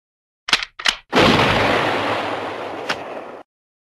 Звуки выстрела, мультфильмов
На этой странице собраны звуки выстрелов из популярных мультфильмов: пистолеты, ружья, лазерные пушки и другие забавные эффекты.
Звук перезарядки та постріл